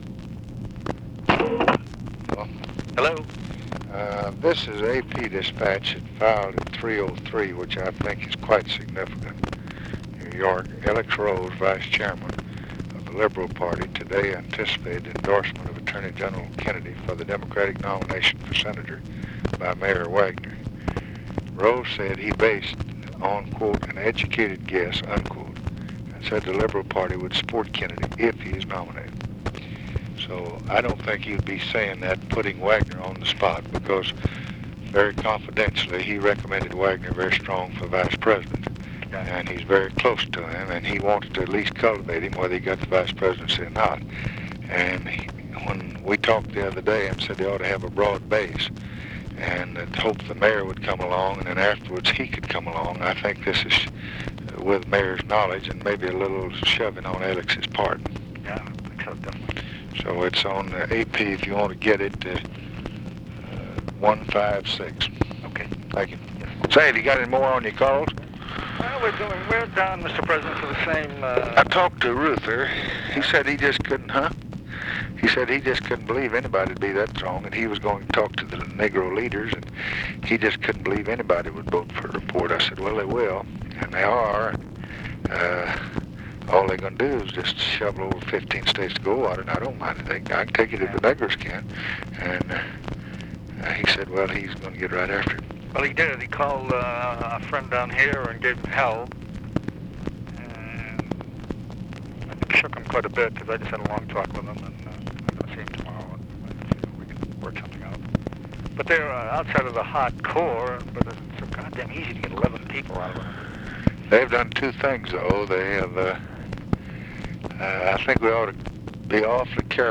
Conversation with KEN O'DONNELL, August 14, 1964
Secret White House Tapes